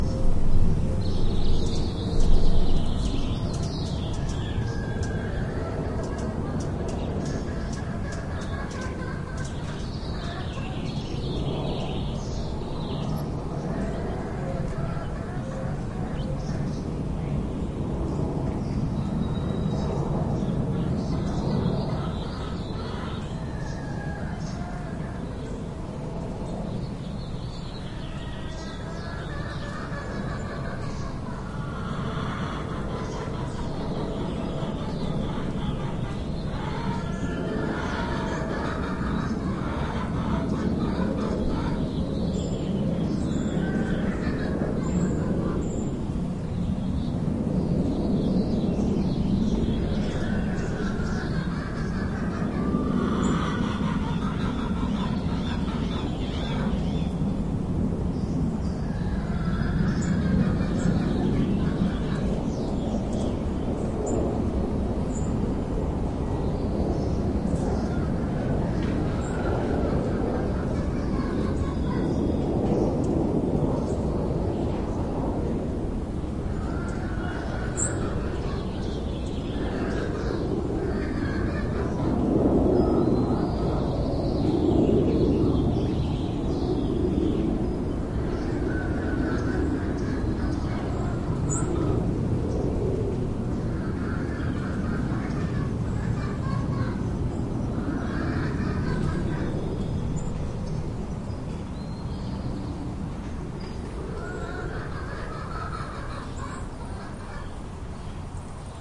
鸡鸣声
描述：声音未处理过。
Tag: 鸡鸣声 农村 大公鸡